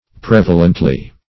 prevalently - definition of prevalently - synonyms, pronunciation, spelling from Free Dictionary Search Result for " prevalently" : The Collaborative International Dictionary of English v.0.48: Prevalently \Prev"a*lent"ly\, adv.